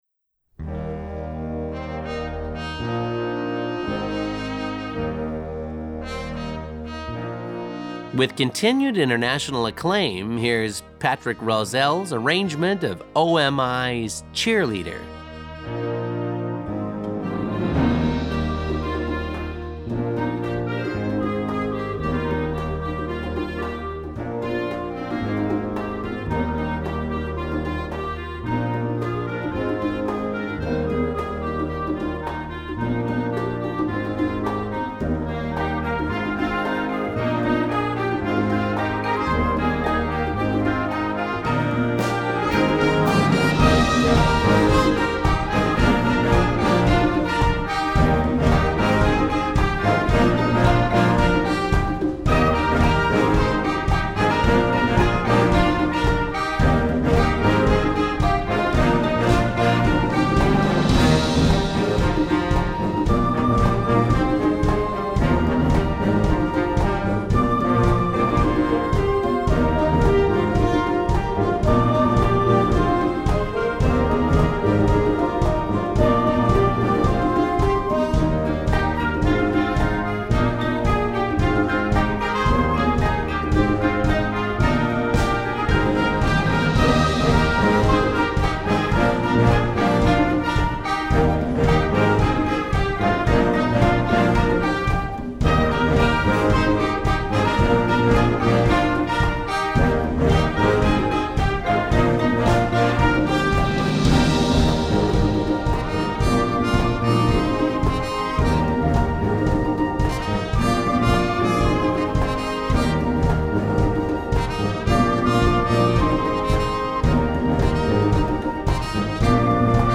Solo Trumpet Feature
Besetzung: Blasorchester